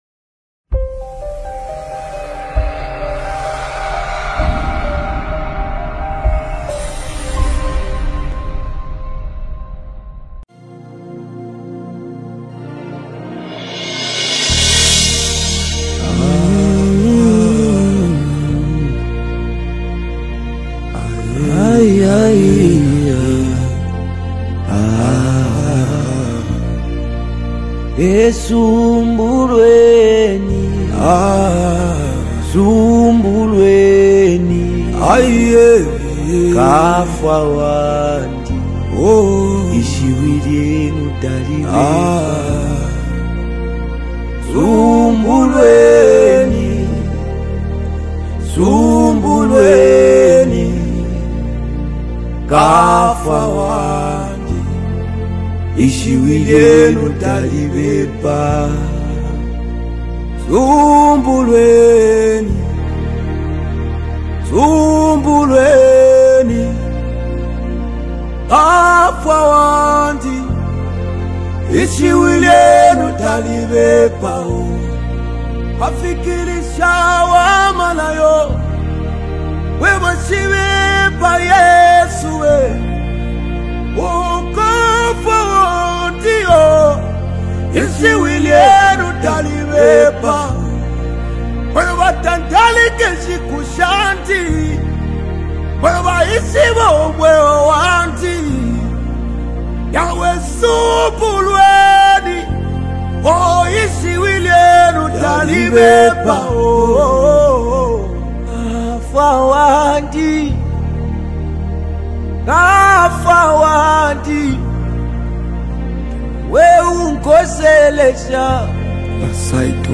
A deeply anointed worship song that exalts God
📅 Category: Zambian Deep Worship Song